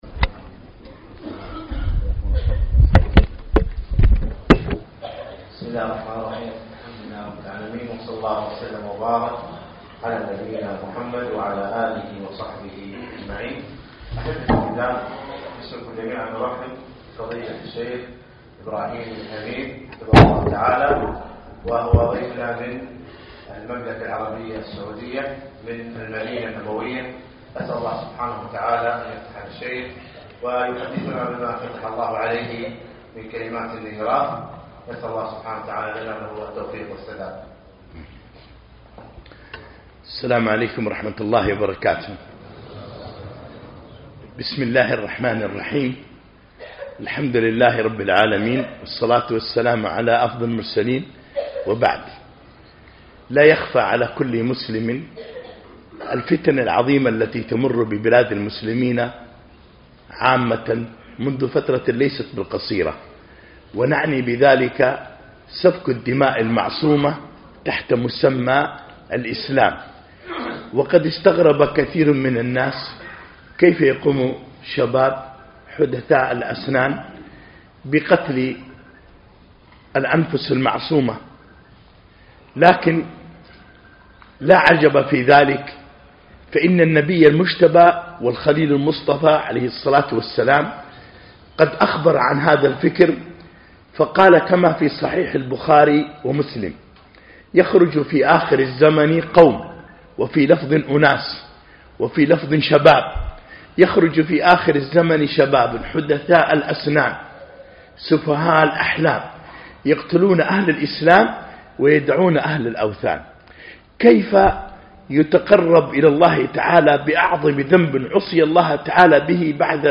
في مسجد عطارد بن حاجب الكويت